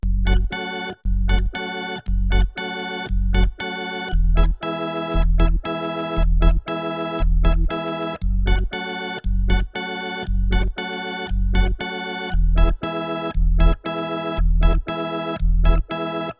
雷鬼风琴3
描述：用哈蒙德B3风琴演奏的欢快的雷鬼旋律。